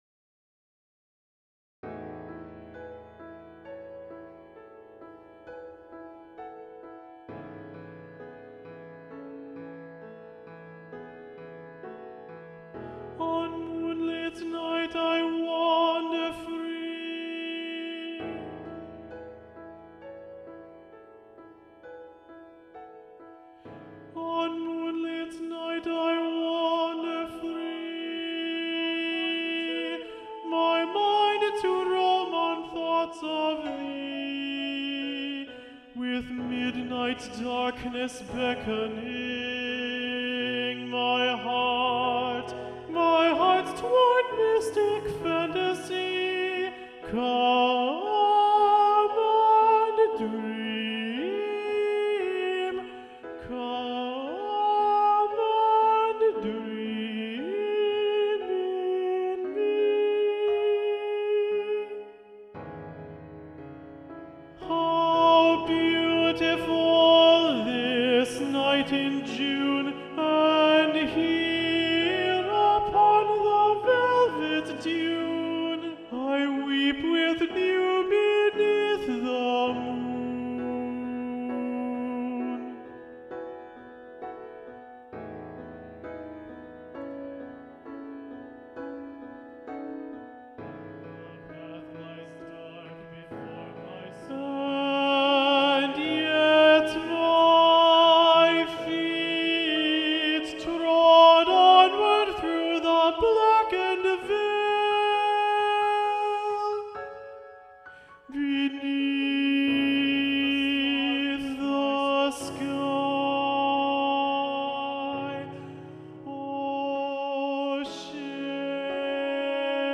Alto
Ballade-to-the-Moon-Alto-Predominant-Daniel-Elder.mp3